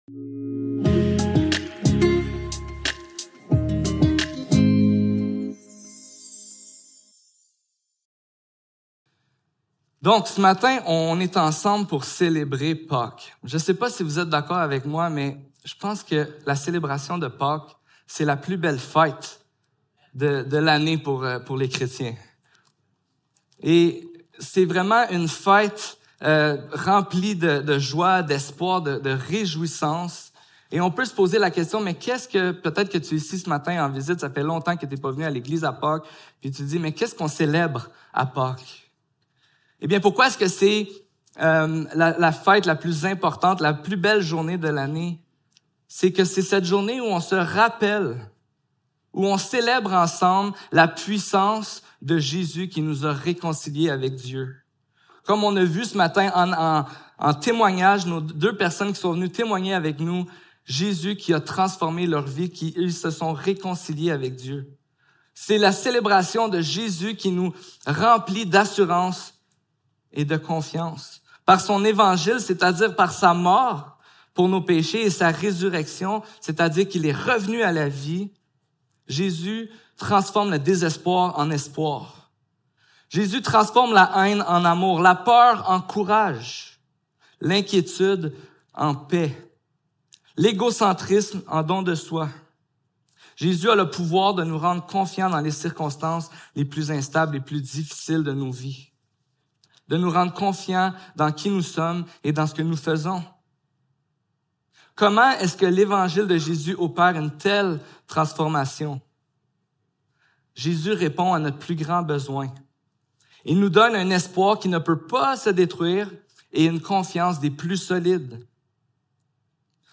1 Corinthiens 15 Service Type: Célébration dimanche matin Qu'est-ce qui fait que la résurrection de Jésus soit une si grande nouvelle ?